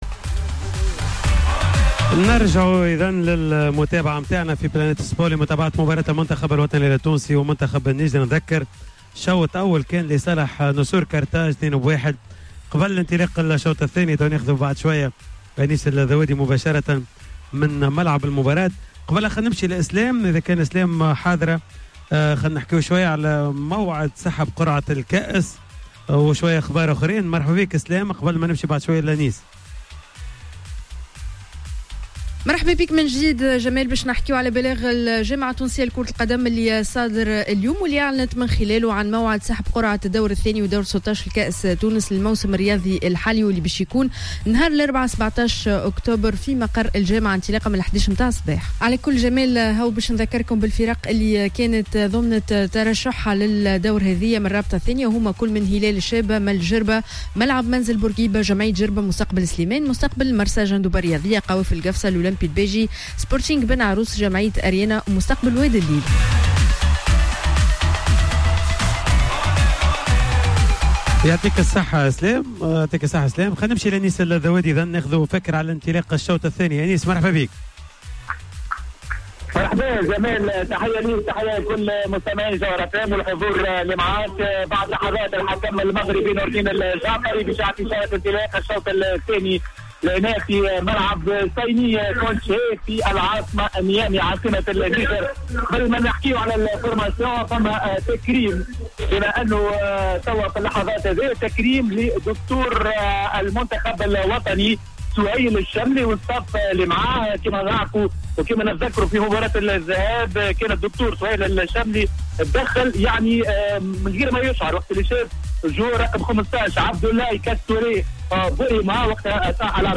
خصصت حصة "Planète Sport" ليوم الثلاثاء 16 أكتوبر 2018 لمتابعة مقابلة النيجر و تونس لحساب الجولة الرابعة من تصفيات بطولة افريقيا 2019 مباشرة من فضاء Yolo بسوسة.